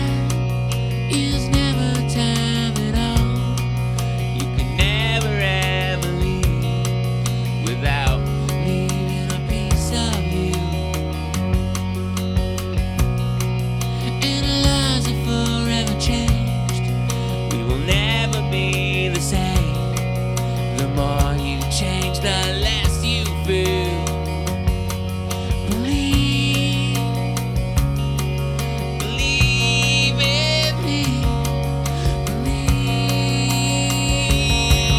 Hard Rock Rock Alternative Indie Rock Adult Alternative
Жанр: Рок / Альтернатива